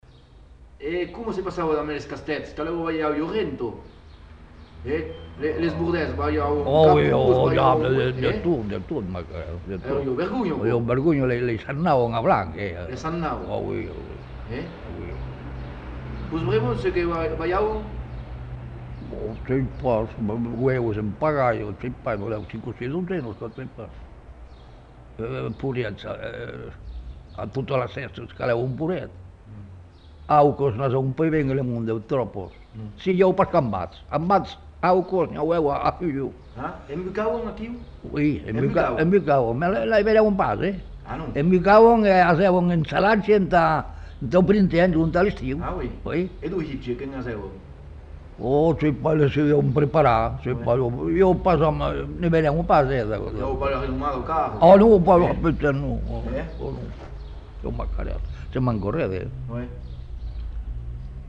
Lieu : Lombez
Genre : témoignage thématique